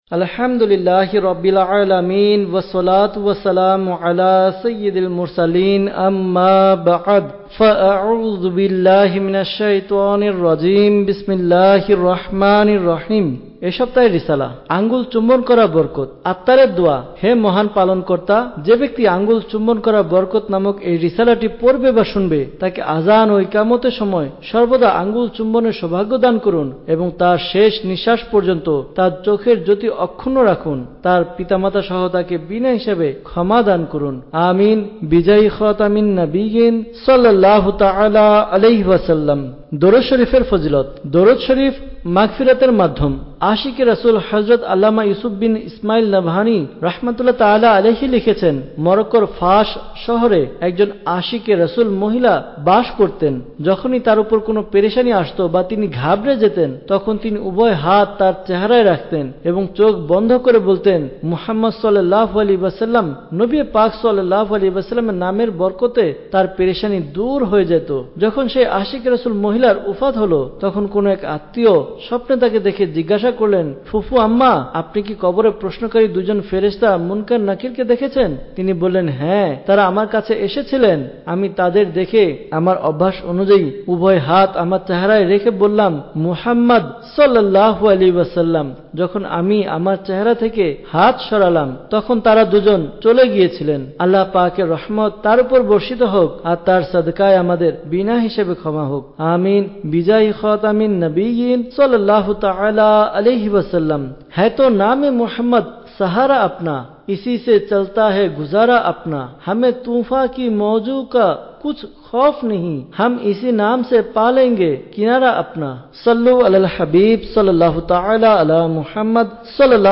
Audiobook – আঙুল চুম্বন করার বরকত (Bangla)